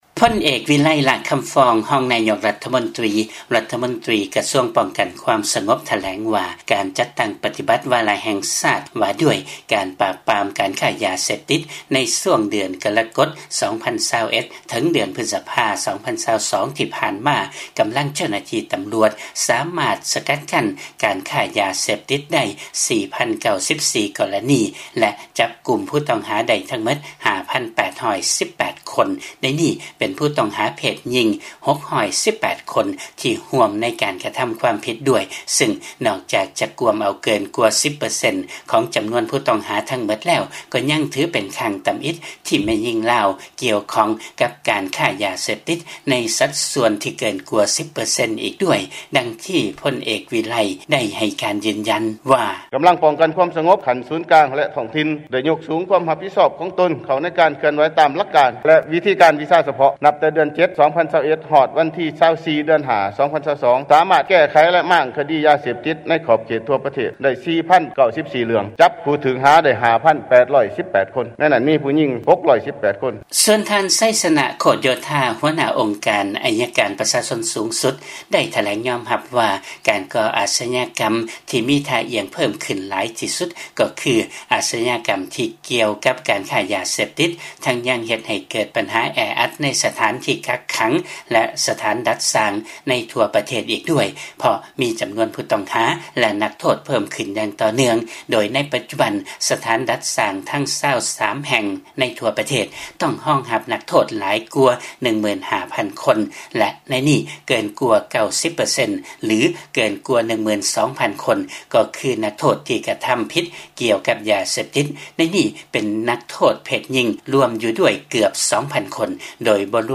ຟັງລາຍງານ ລັດຖະມົນຕີຢືນຢັນວ່າ ແມ່ຍິງລາວເຂົ້າໄປກ່ຽວຂ້ອງໃນອາຊະຍາກຳ ທີ່ກ່ຽວກັບການຄ້າຢາເສບຕິດຫຼາຍຂຶ້ນ